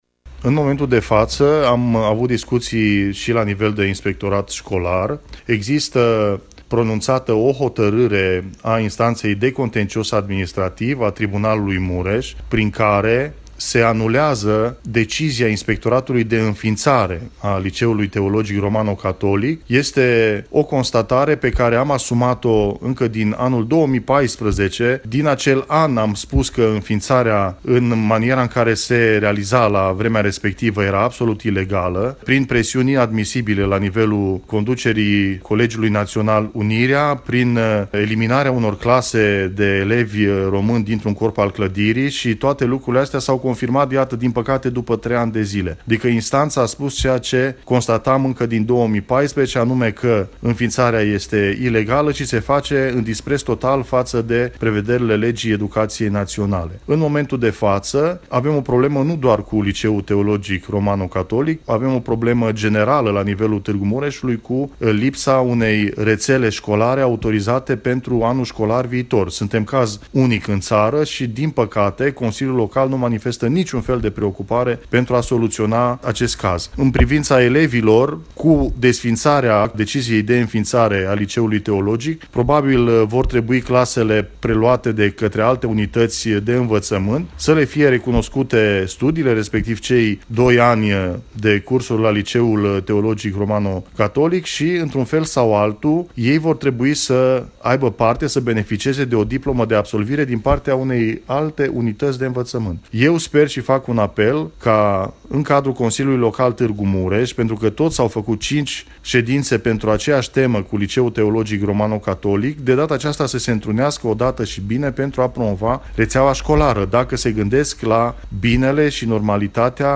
Deputatul Marius Pașcan a declarat pentru Radio Tg.Mureș că este îngrijorat pentru soarta elevilor de la acest liceu, elevi care probabil vor trebui preluați de alte unități de învățământ din oraș: